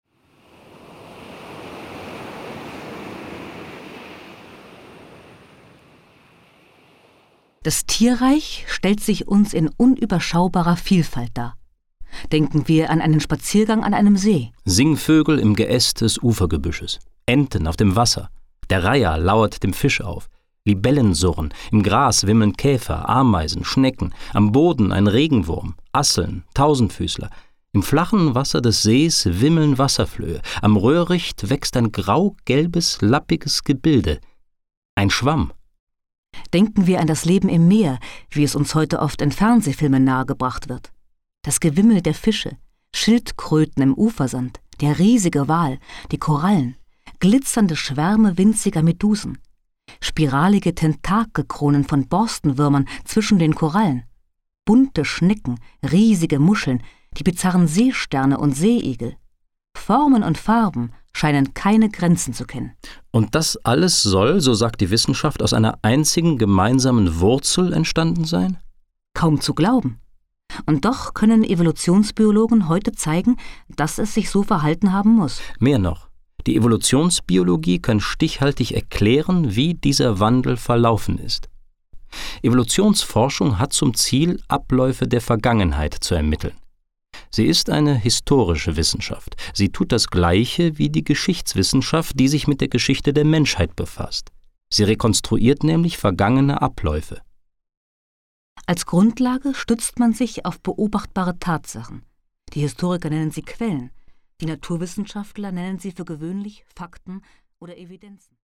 Ein Hörbuch zur Entstehung des Lebens in zwei Teilen!